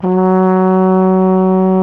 TENORHRN F#1.wav